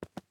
Player Character SFX / Footsteps